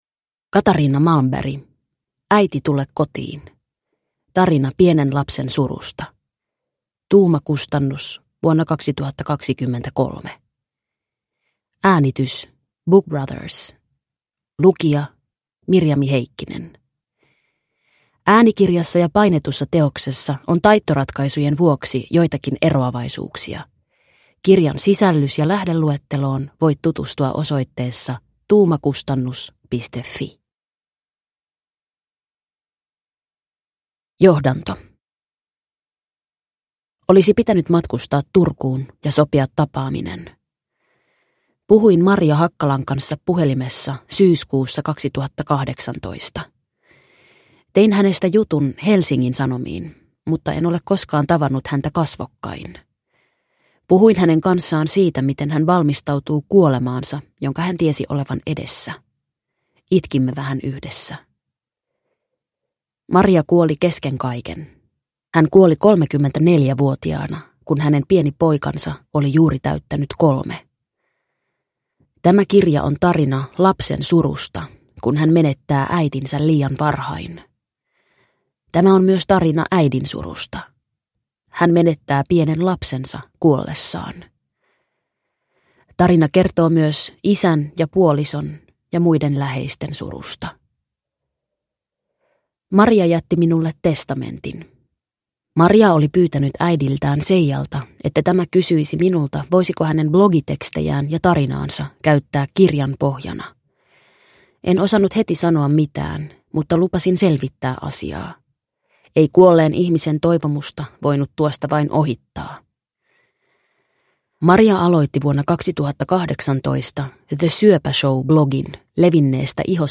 Äiti, tule kotiin! – Ljudbok